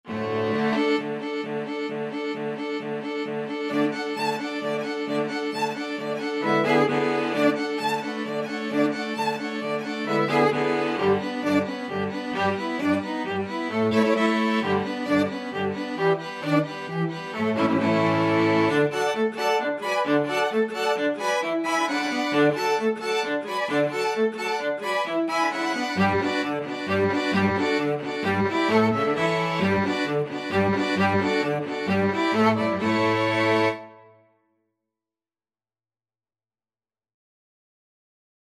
Violin 1Violin 2ViolaCello
Allegro =132 (View more music marked Allegro)
2/4 (View more 2/4 Music)
Classical (View more Classical String Quartet Music)